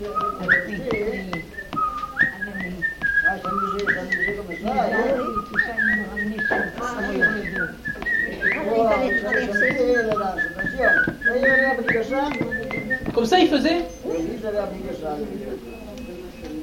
Genre : chant
Effectif : 1
Type de voix : voix d'homme
Production du son : sifflé
Danse : polka piquée